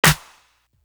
Desire Clap.wav